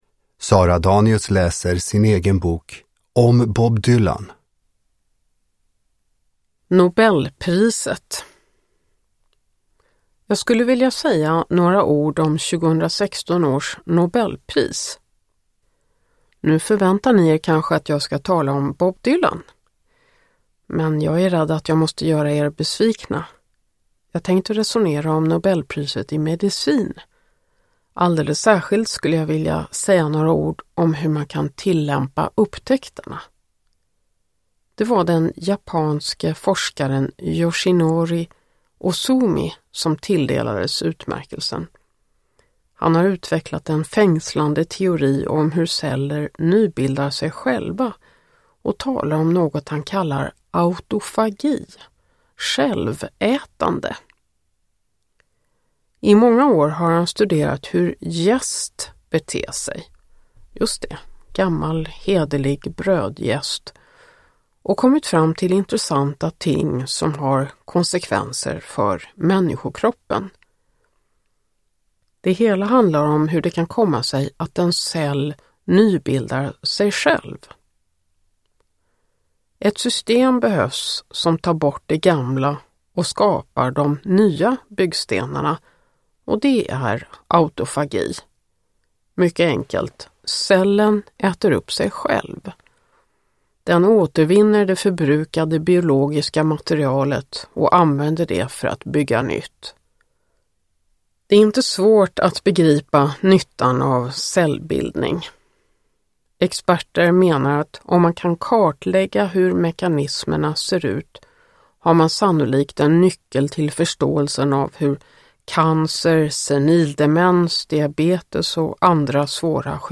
Om Bob Dylan – Ljudbok
Uppläsare: Sara Danius